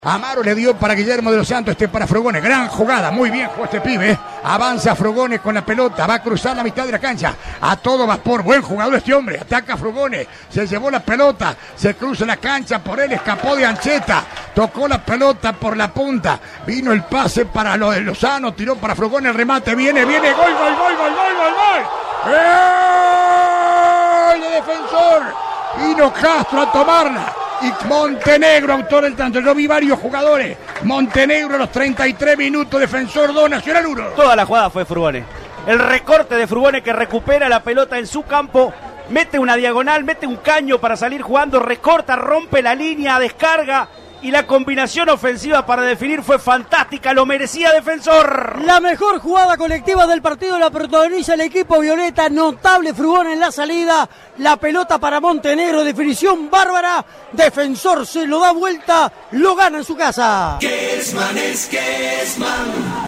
GOLES RELATADOS POR ALBERTO KESMAN